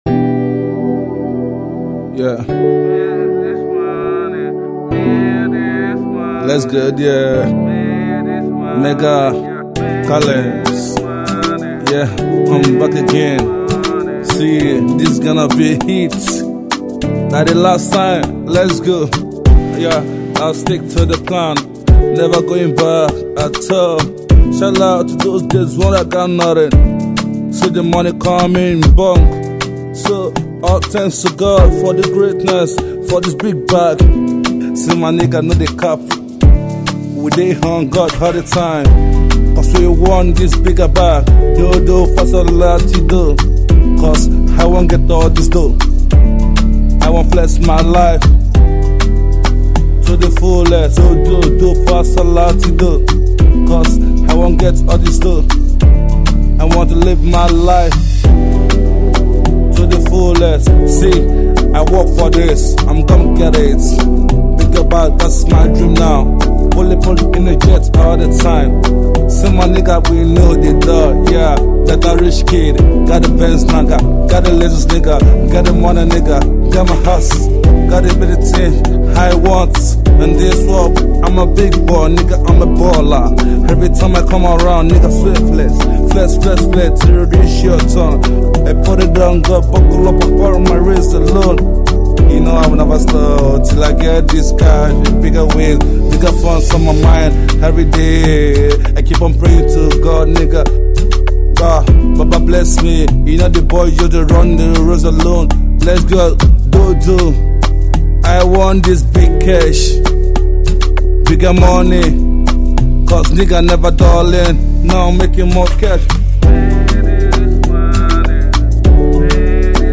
is a motivational hit